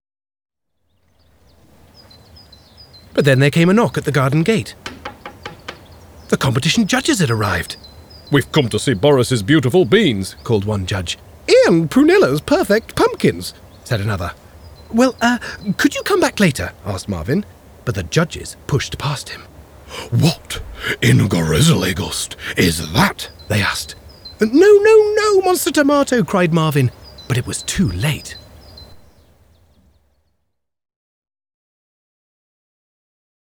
• Male
• Standard English R P